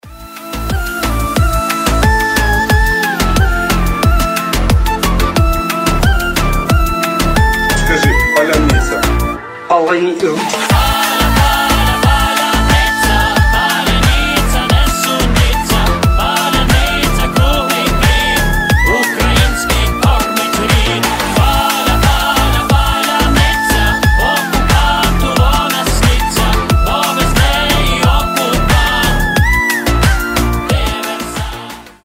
• Качество: 320, Stereo
фолк